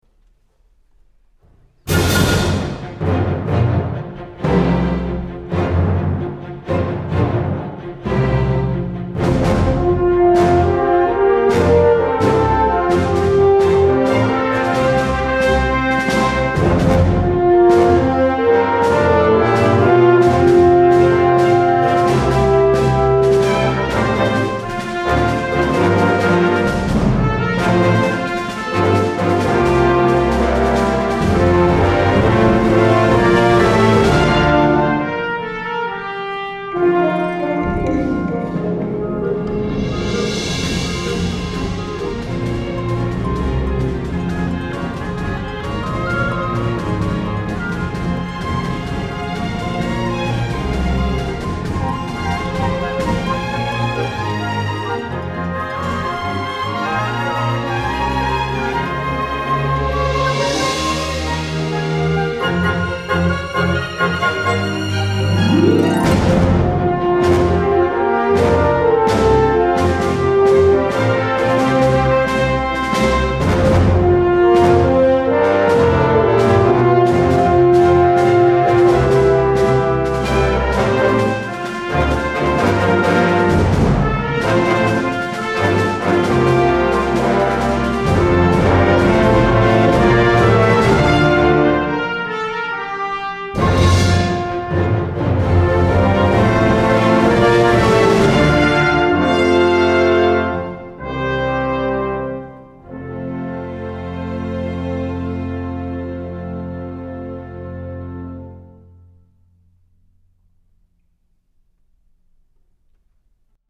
western